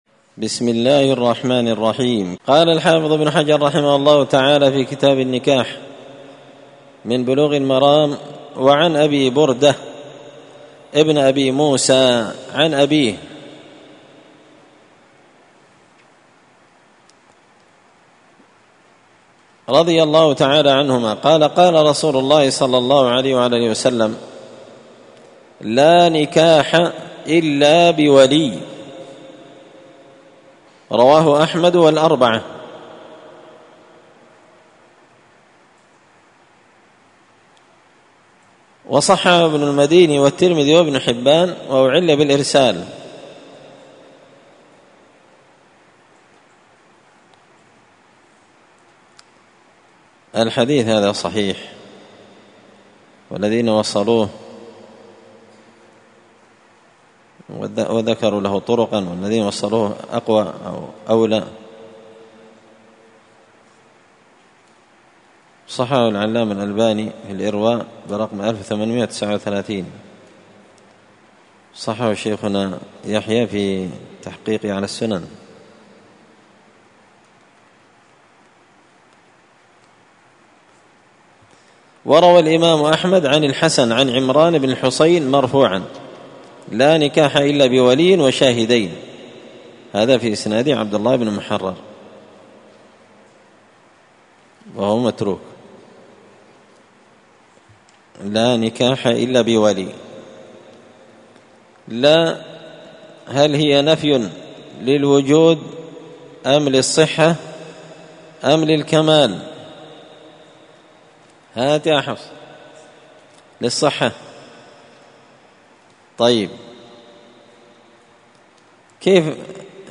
كتاب النكاح من سبل السلام شرح بلوغ المرام لابن الأمير الصنعاني رحمه الله تعالى الدرس – 7 تابع أحكام النكاح
مسجد الفرقان_قشن_المهرة_اليمن